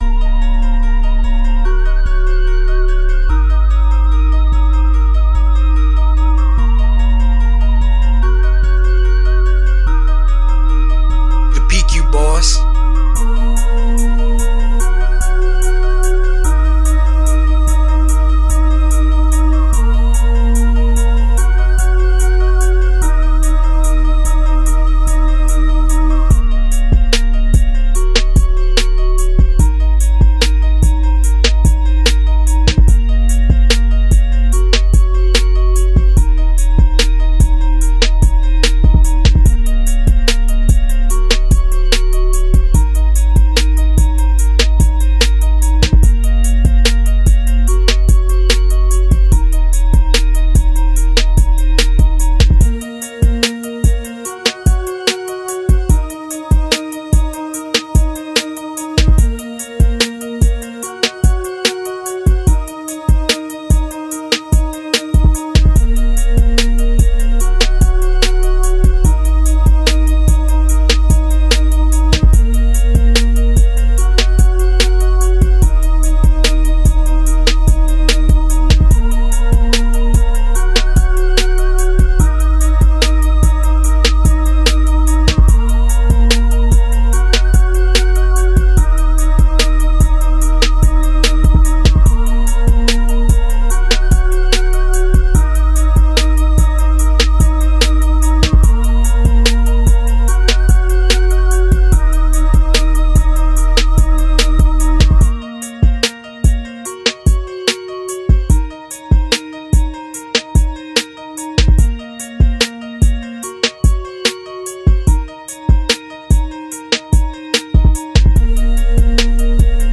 Street record that you can tell a story to.